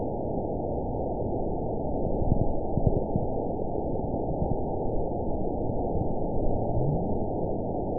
event 920523 date 03/28/24 time 23:00:33 GMT (1 year, 2 months ago) score 9.53 location TSS-AB05 detected by nrw target species NRW annotations +NRW Spectrogram: Frequency (kHz) vs. Time (s) audio not available .wav